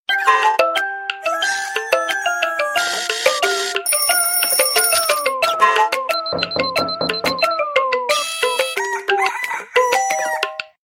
• Качество: 128, Stereo
будильник
Весёлая мелодия на смс